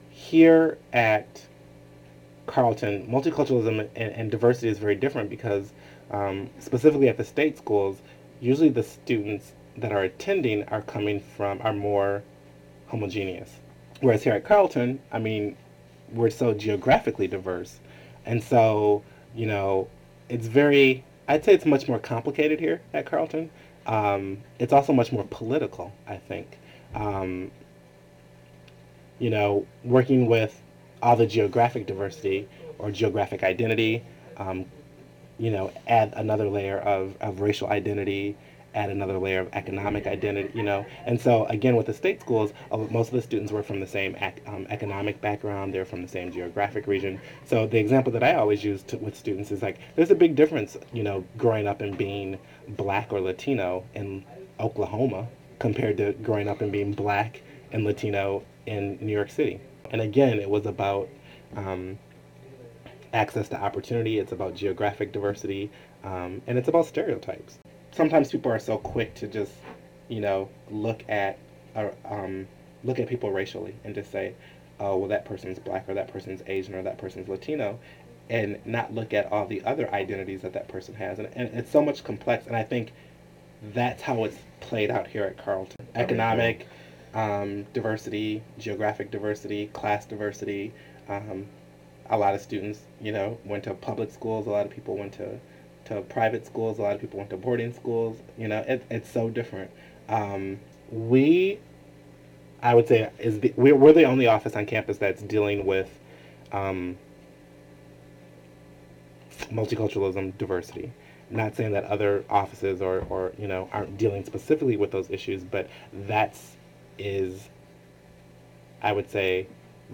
Interview
Format Audio Cassette